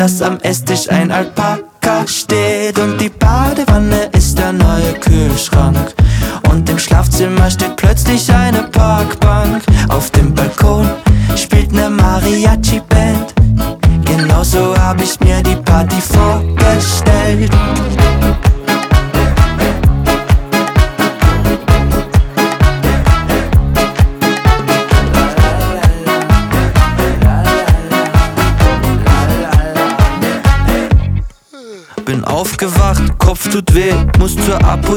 2025-05-16 Жанр: Поп музыка Длительность